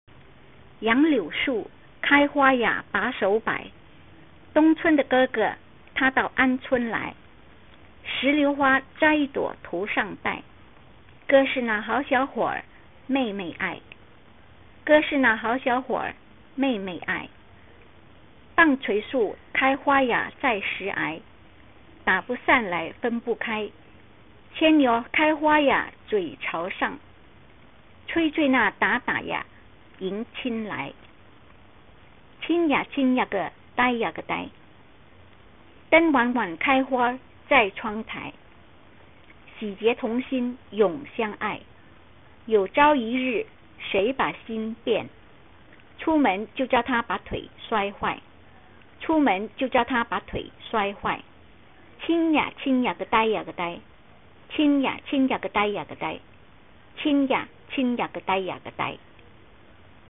Lyrics in HanYuPinYin read here
DengWanWan_read.mp3